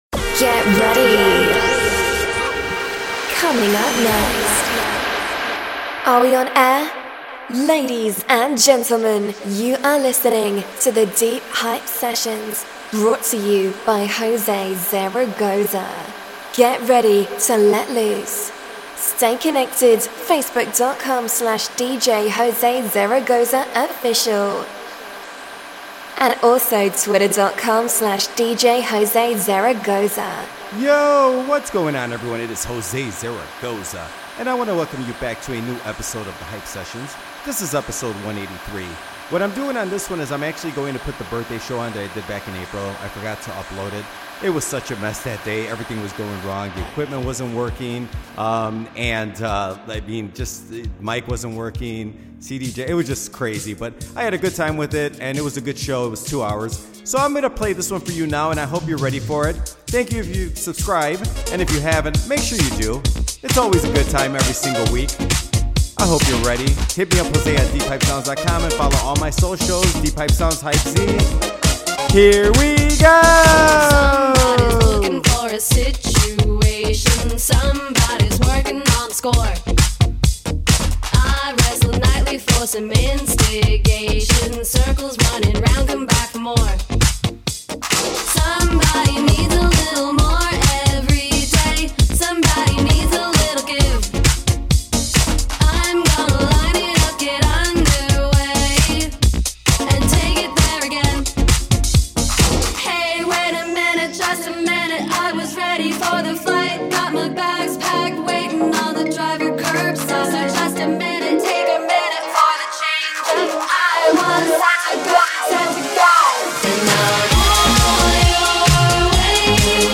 This show was recorded live April 18th, 2025.